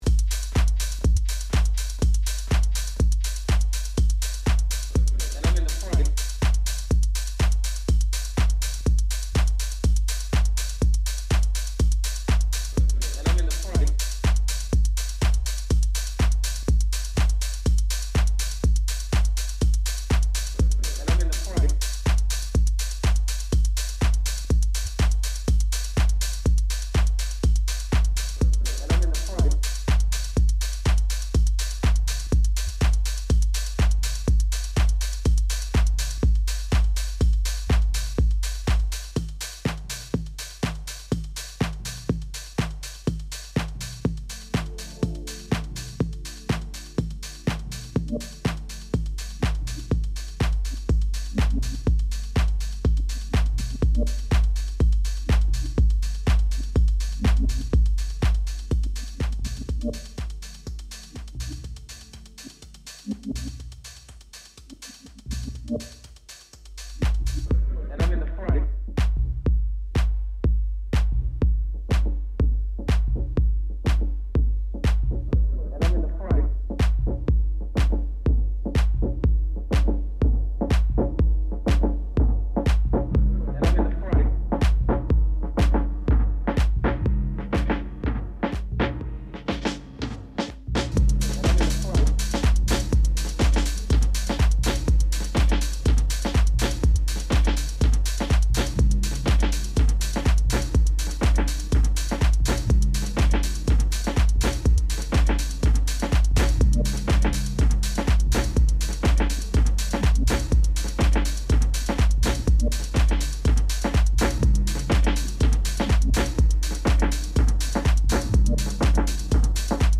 deeper shades of house music
Perfect for the current sunshine.